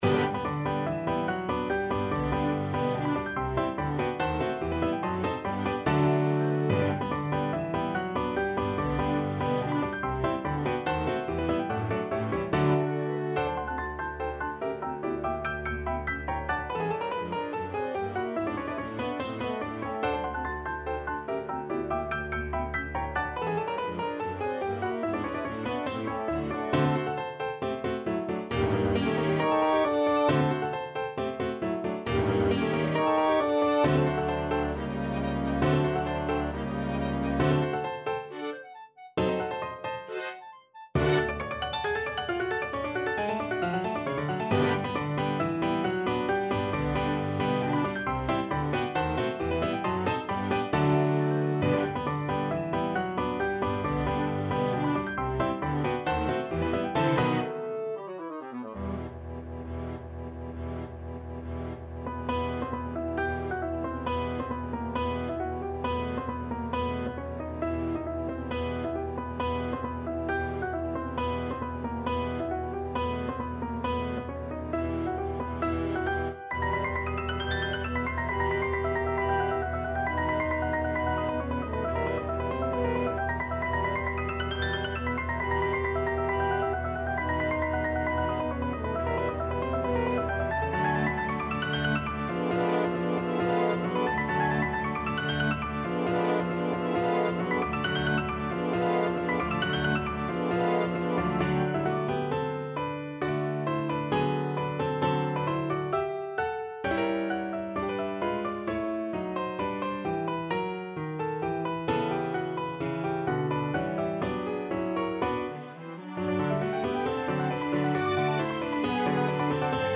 Classical
Orchestra version